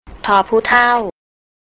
ทอ-ผู้-เท่า
tor poo-tao
table (low tone)